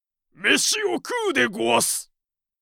パロディ系ボイス素材　5
元ネタが何かしらの作品中に含まれているor作品にまつわるタイプの声素材